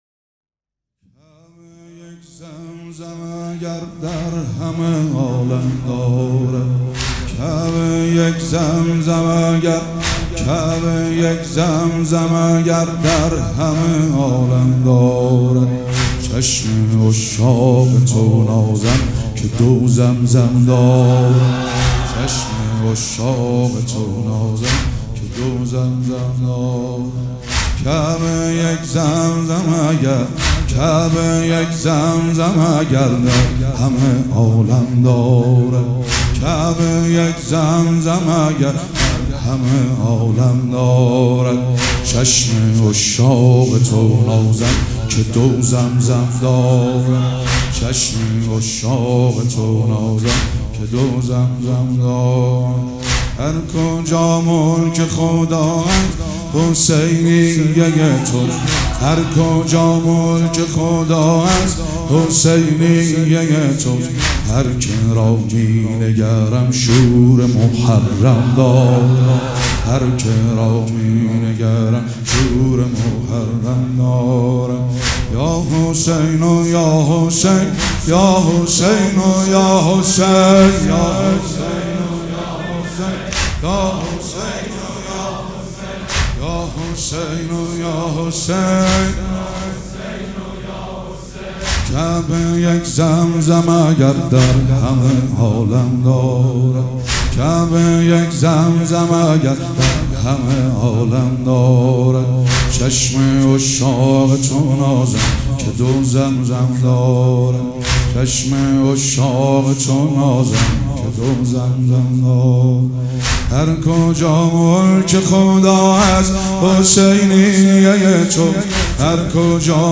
مراسم عزاداری شب پنجم